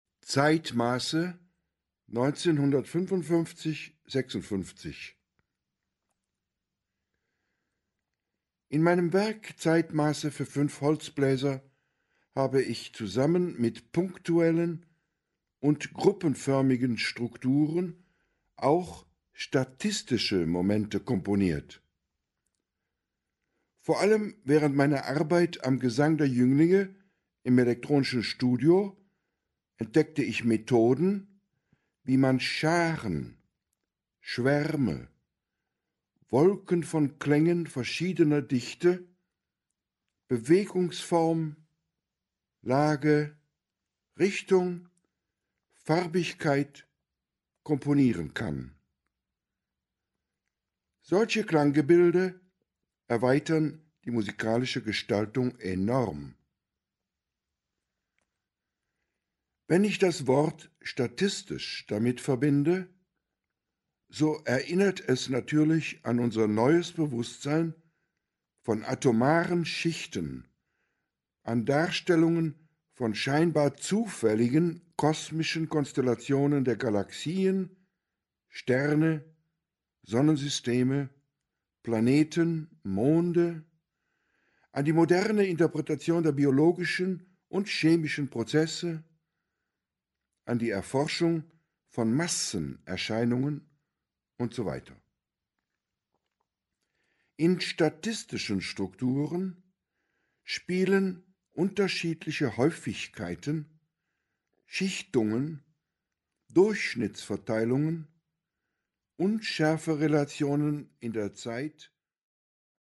Home Special Edition Text-CD Stockhausen Special Edition Text-CD 4 Von Webern zu Debussy / Vortrag 1954 Statistische Form Track Number: 1 Duration : 38:00 Lesung ZEITMASZE (1955 / 56) Track Number: 2 Duration : 34:00